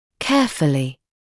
[‘keəfəlɪ][‘кэафэли]внимательно, тщательно; осторожно, осмотрительно